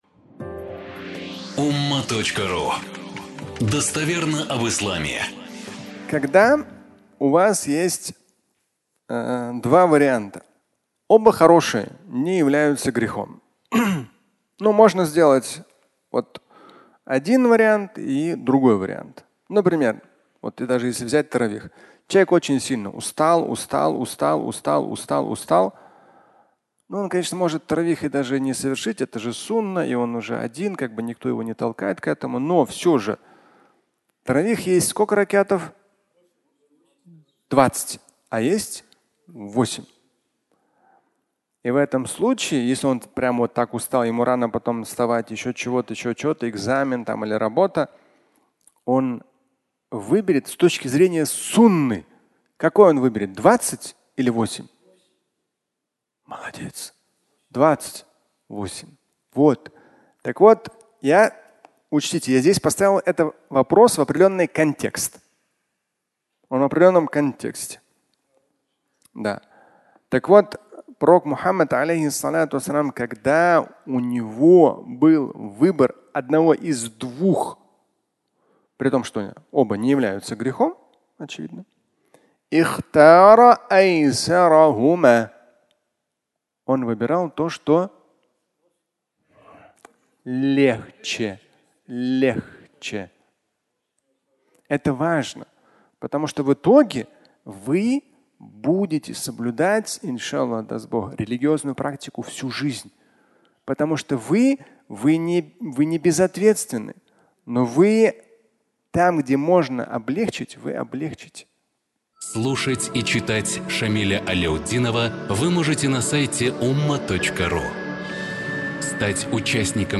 Выбор легкого (аудиолекция)
Фрагмент пятничной лекции, в котором Шамиль Аляутдинов цитирует хадис о легкости и объясняет пагубность усложнений.